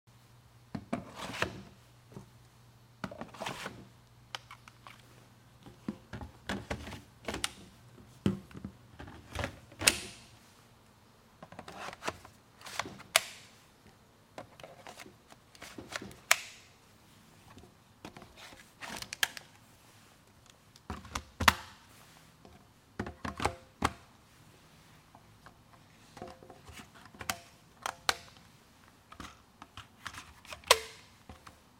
Drone ASMR DJI M300, sound effects free download
Drone ASMR - DJI M300, M30, Mavic 3, Mini 3